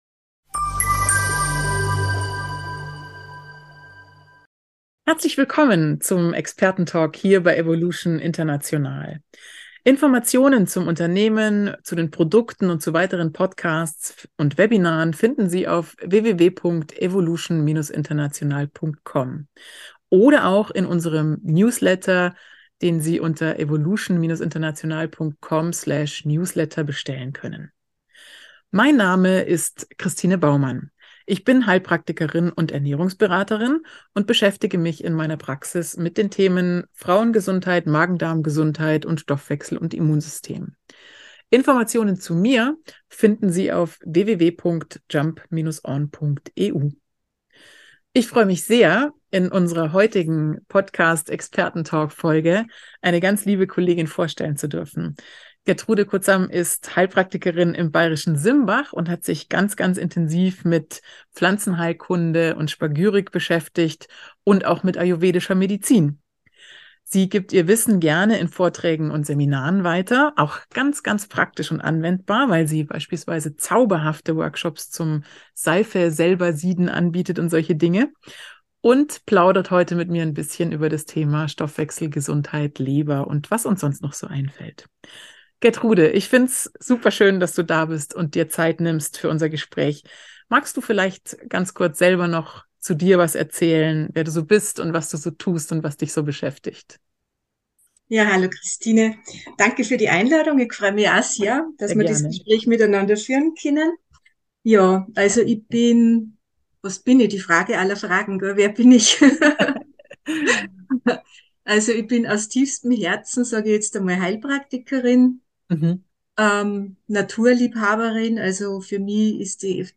Beschreibung vor 2 Jahren 2 Heilpraktikerinnen im Gespräch über psychologische Grundlagen in der Praxis, gesprächstherapeutische Anliegen und die Besonderheiten der ayurvedischen Medizin.